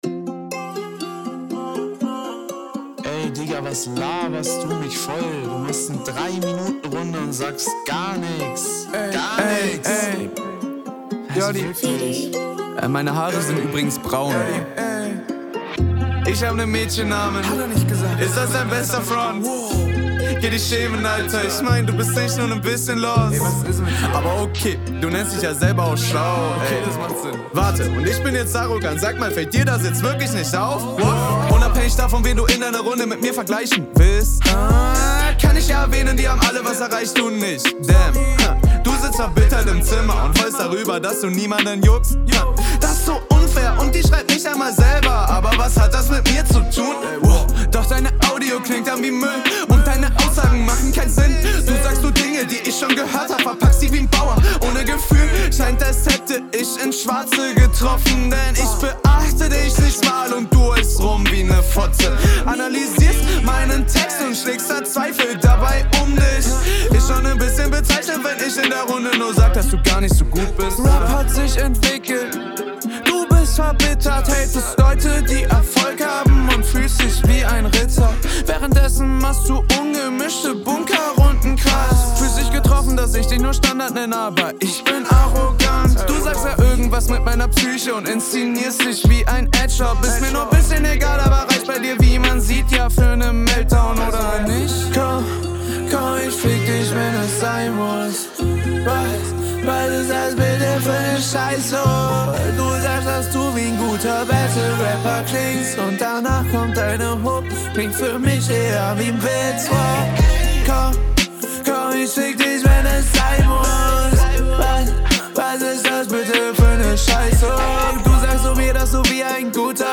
Diesmal hast du den abwechslungsreicheren Flow, was mir gut gefällt.